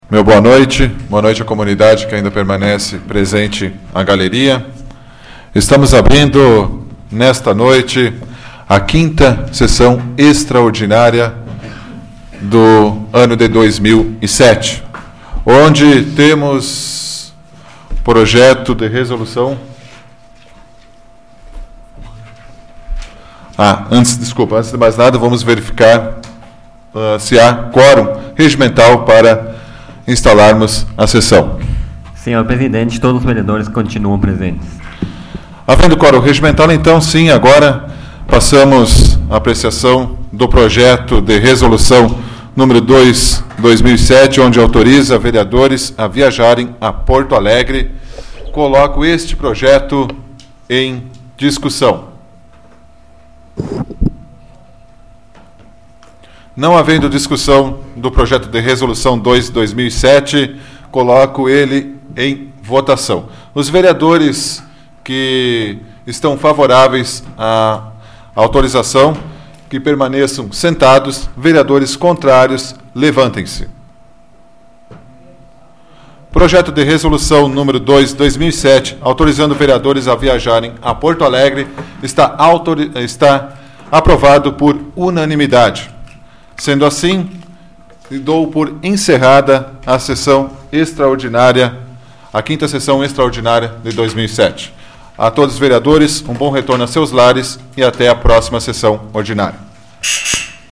Áudio da 35ª Sessão Plenária Extraordinária da 12ª Legislatura, de 09 de abril de 2007